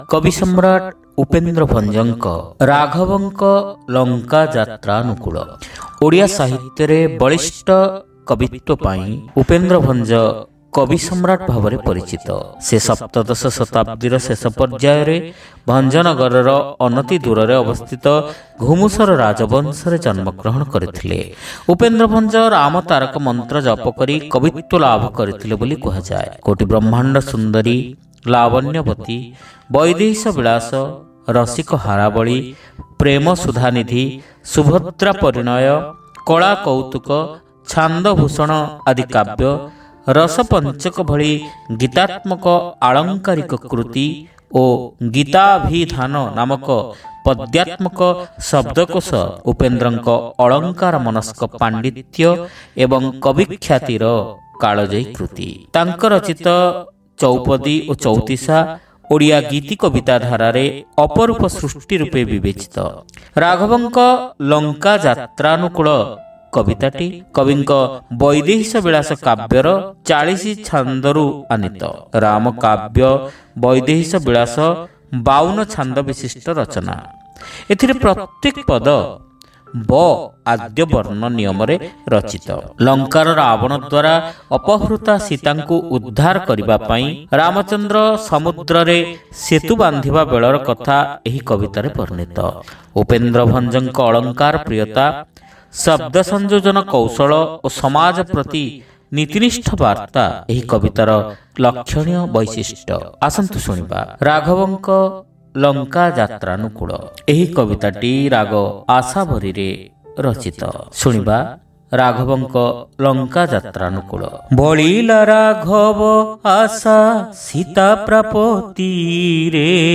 ଶ୍ରାବ୍ୟ କବିତା : ରାଘବଙ୍କ ଲଙ୍କା ଯାତ୍ରାନୁକୁଳ